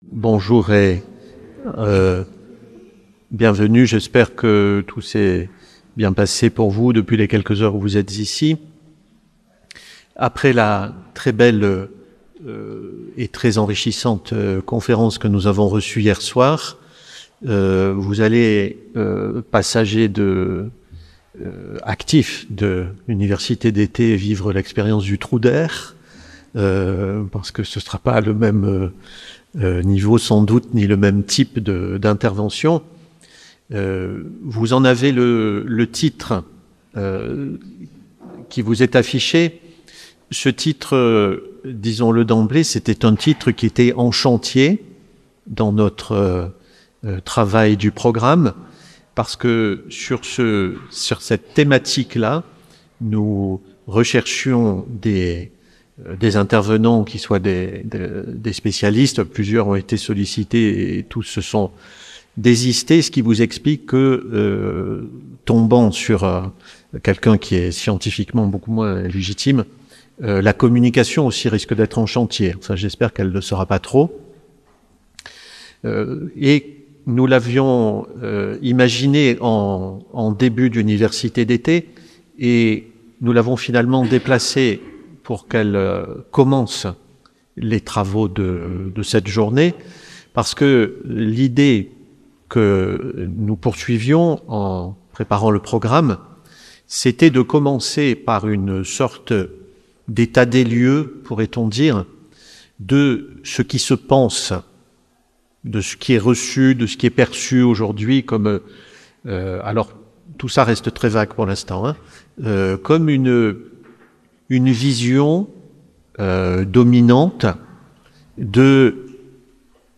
Ste Baume. Université d'été